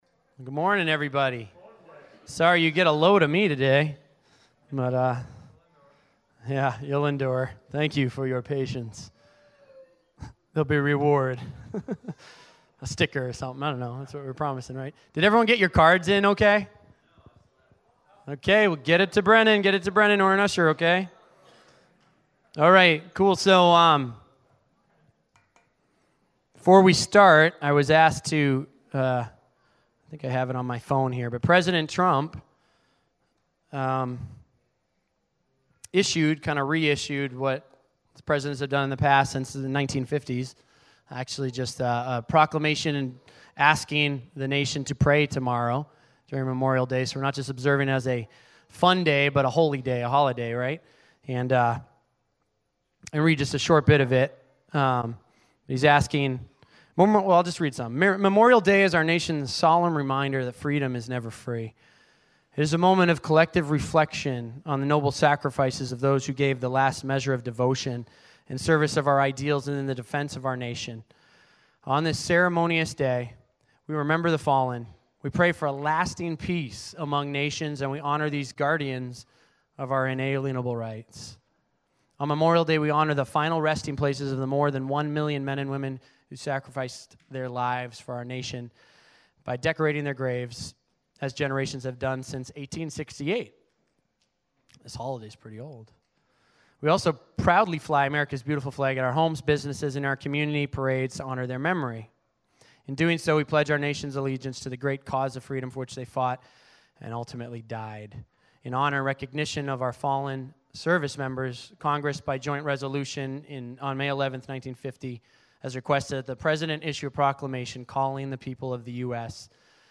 5-27-17-First-Service-Sermon.mp3